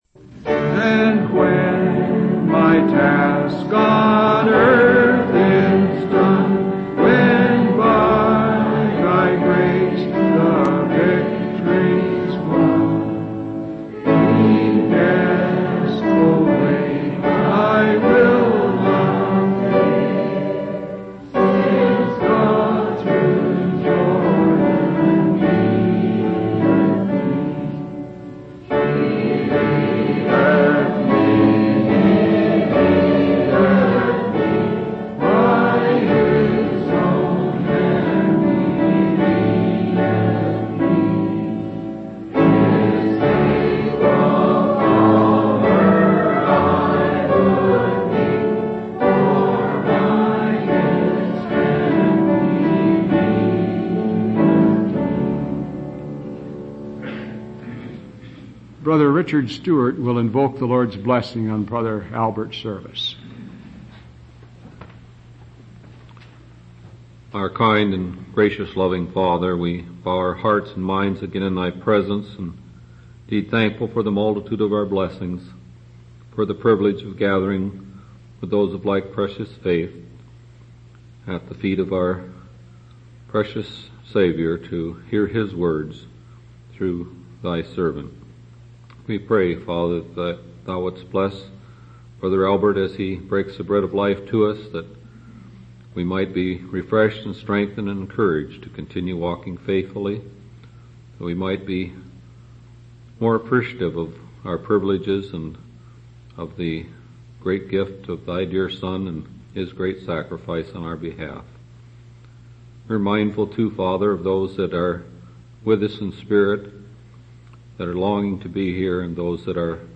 From Type: "Discourse"
Given in Seattle, WA 1992